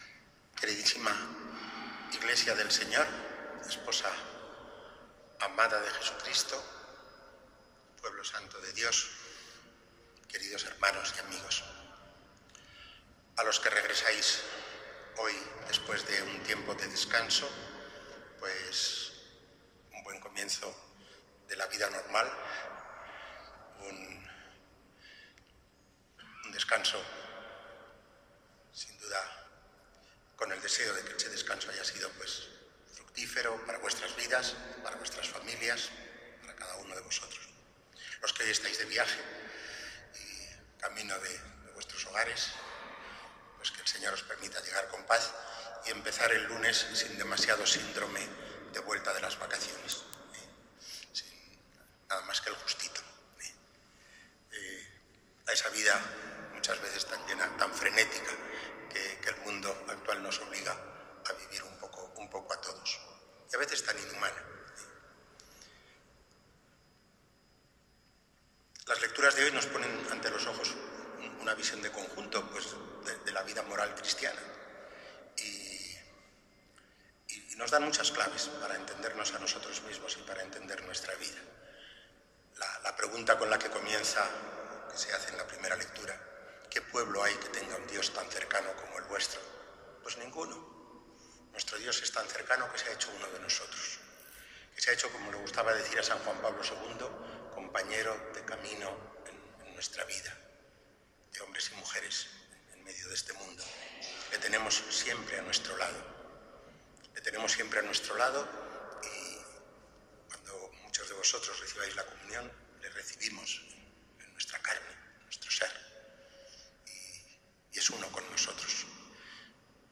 2_SEPTIEMBRE_2018_Homilia_Catedral.mp3